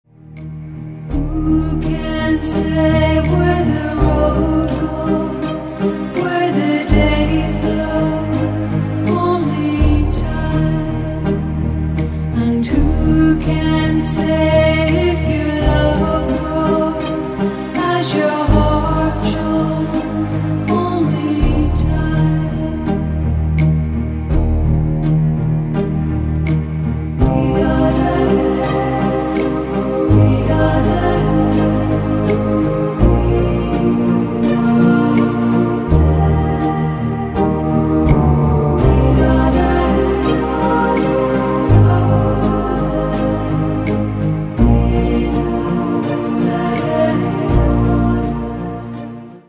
• Качество: 192, Stereo
красивые